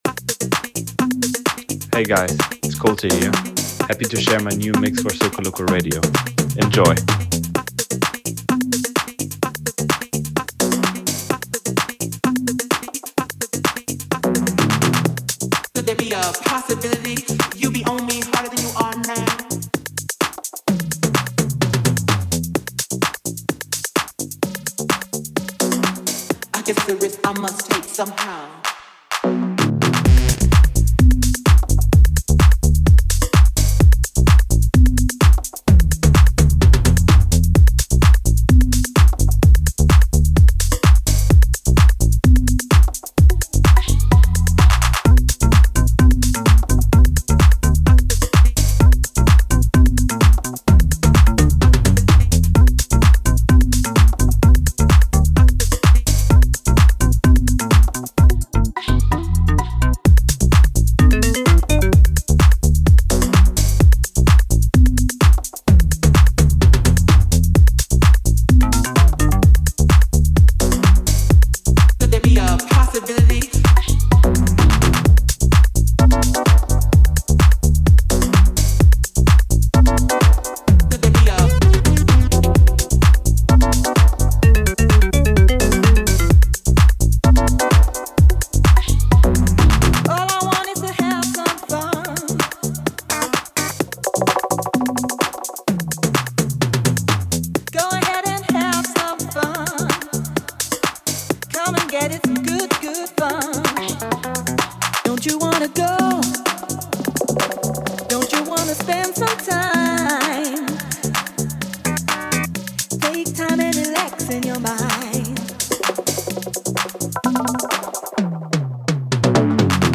Bringing you new mixes from the best DJs in the world.